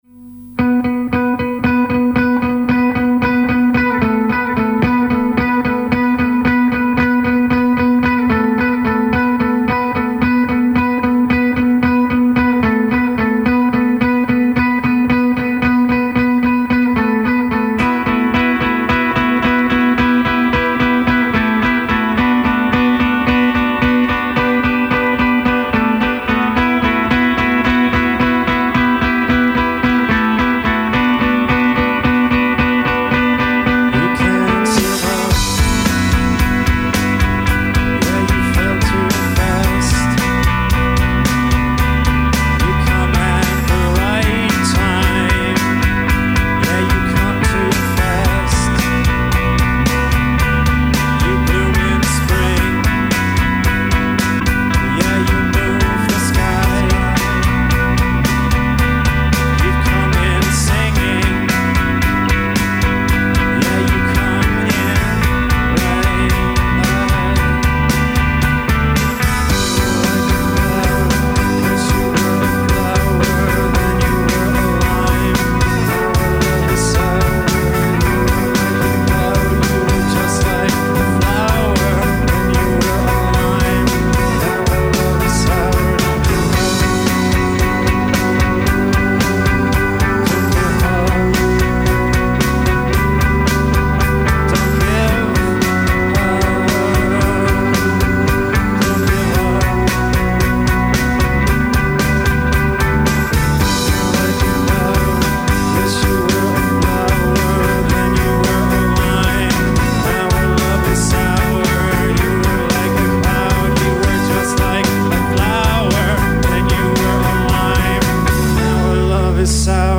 enregistrée le 27/08/2002  au Studio 105